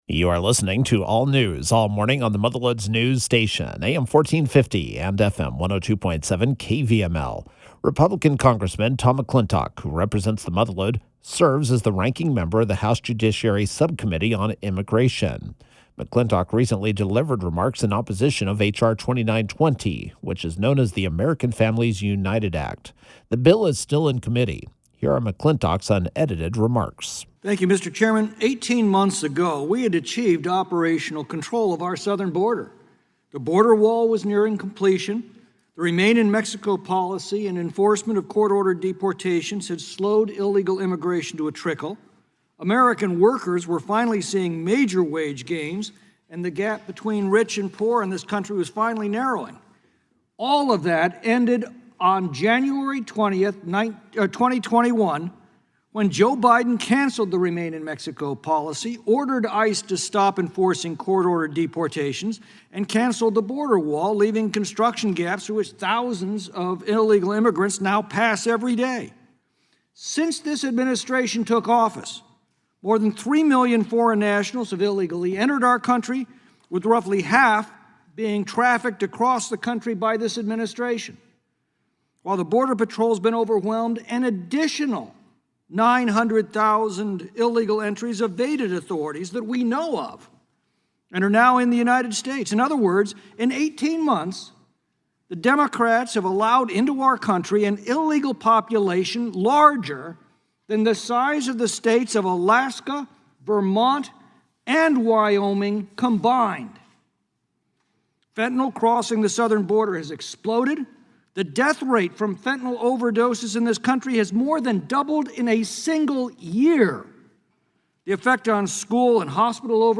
Congressman Tom McClintock serves as the Ranking Member of the House Judiciary Subcommittee on Immigration. The Congressman recently delivered remarks in opposition to H.R. 2920 (the American Families United Act) at a hearing held by the full House Judiciary Committee.